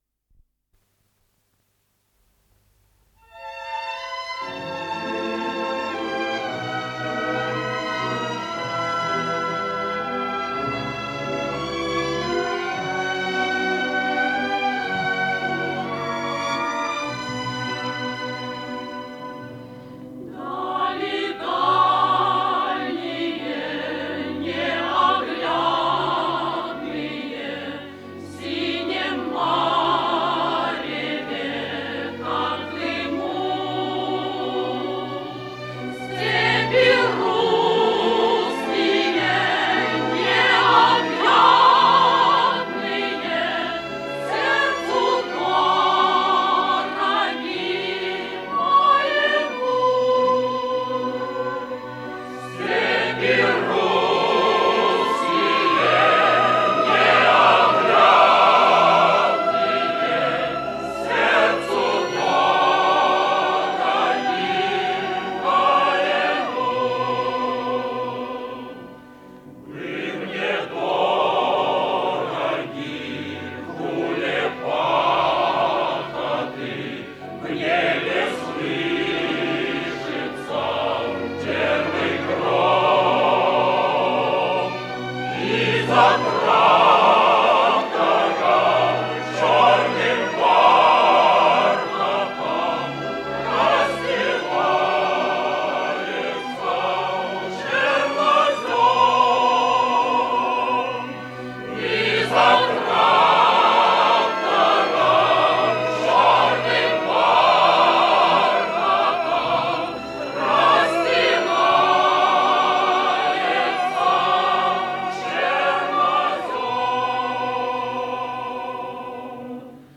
Исполнитель: Артисты театра им. Моссовета
Название передачи Дали неоглядные Подзаголовок Радиокомпозици по спектаклю театра им. Моссовета